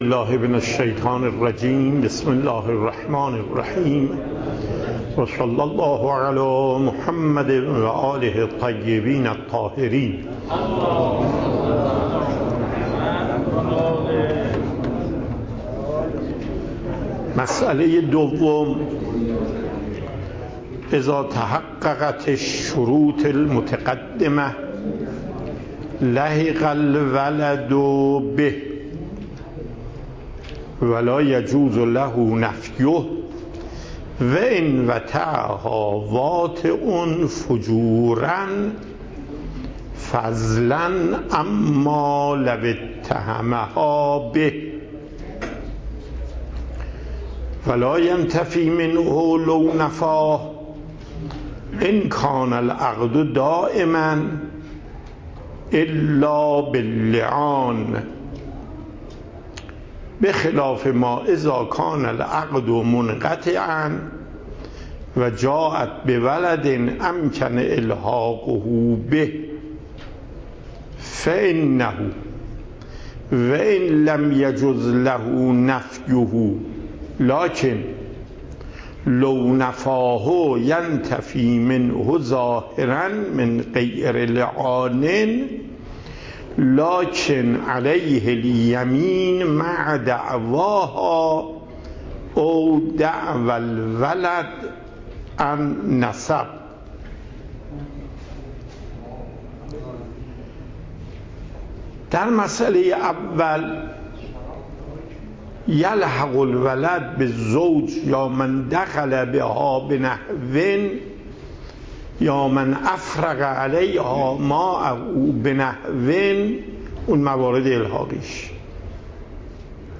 صوت درس
درس فقه آیت الله محقق داماد